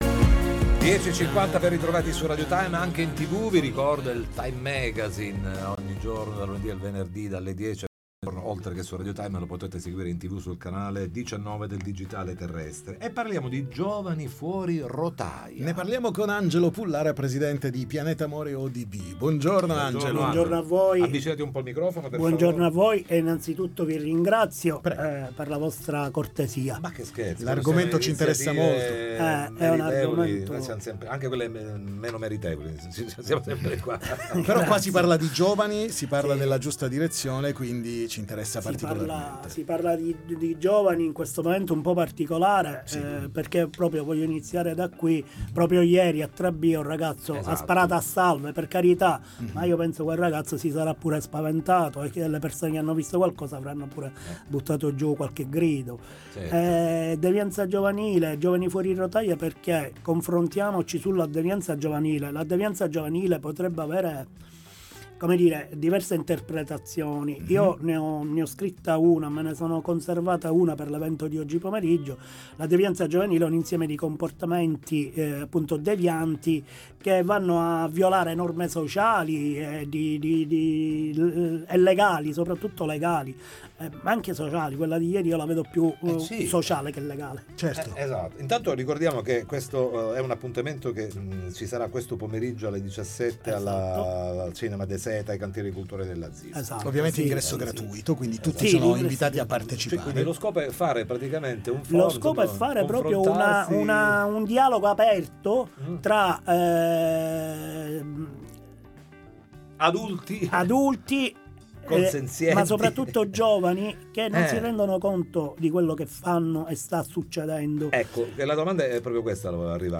Interviste Time Magazine